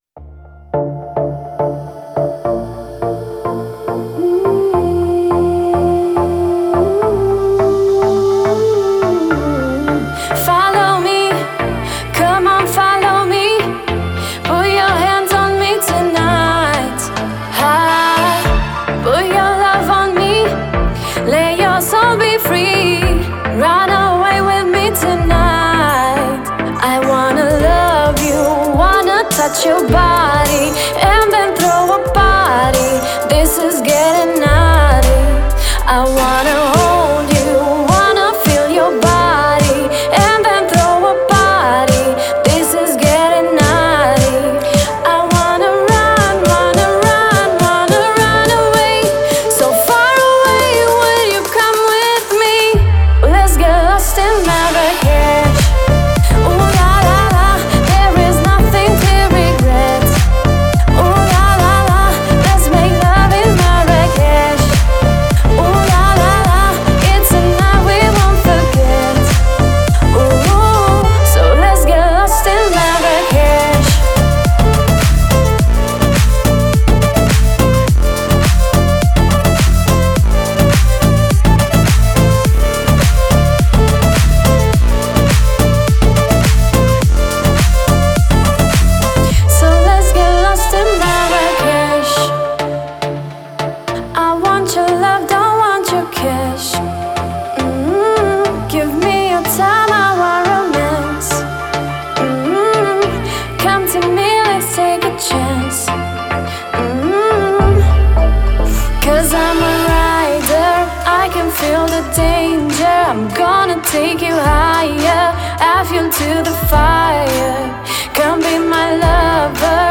это яркое произведение в жанре этно-поп